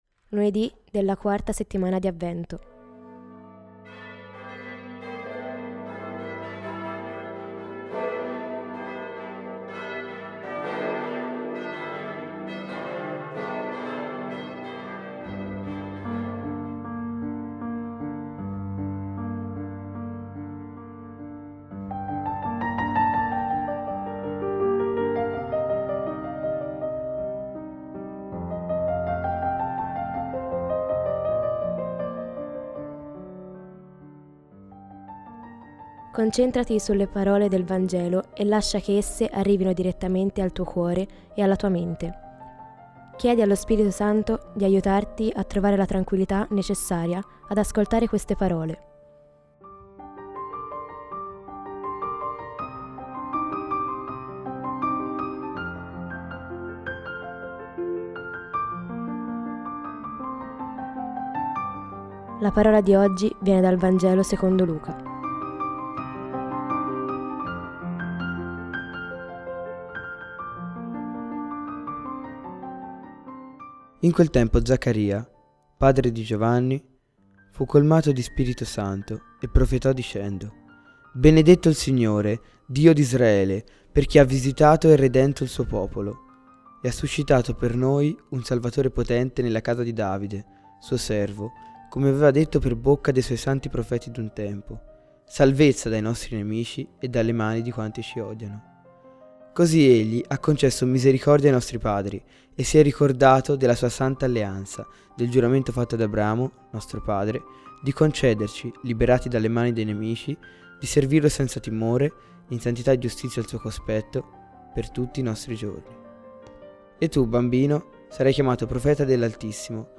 Voci narranti
Musica di Paul de Senneville: Mariage d’Amour